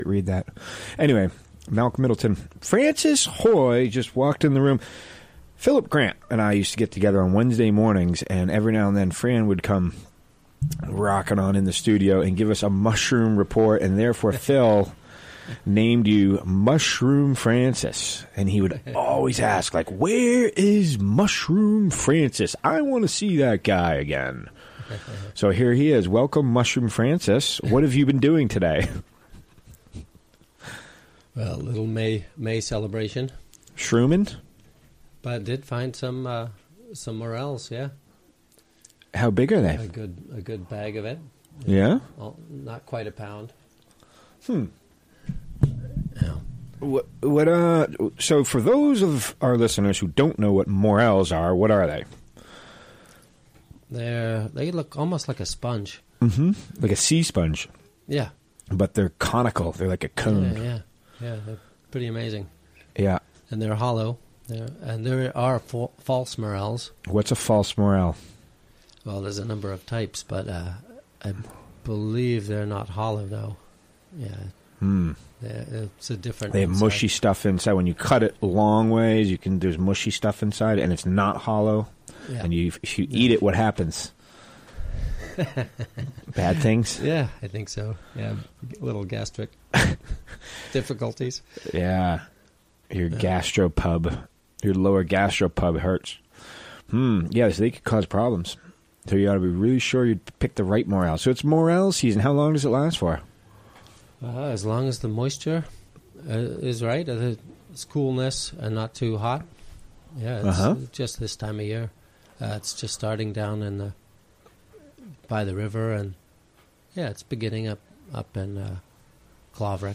Recorded during the WGXC Afternoon Show Monday, May 1, 2017.